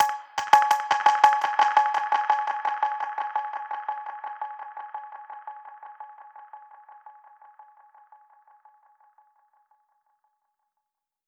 DPFX_PercHit_B_85-04.wav